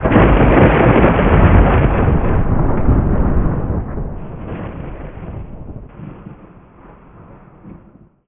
thunderCrack.wav